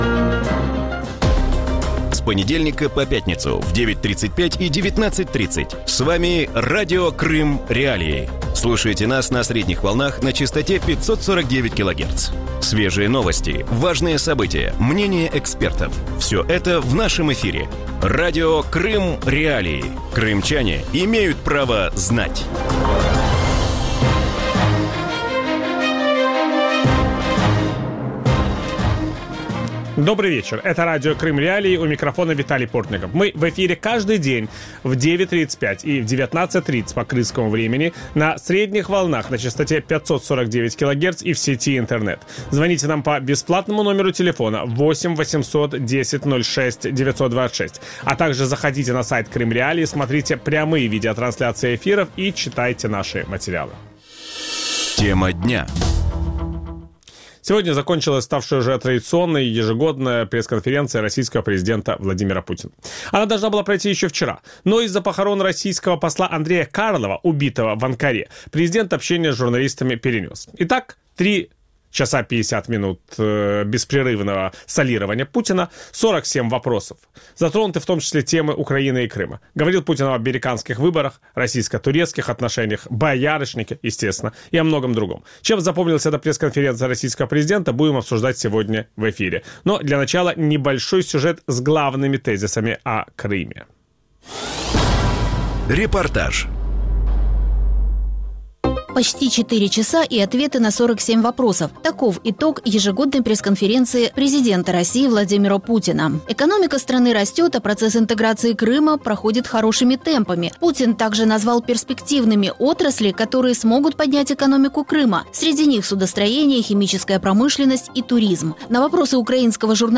В вечернем эфире Радио Крым.Реалии обсуждают итоги традиционной итоговой пресс-конференции российского президента. Какие главные тезисы озвучил Владимир Путин и чем итоговая пресс-конференция 2016 года отличается от предыдущих?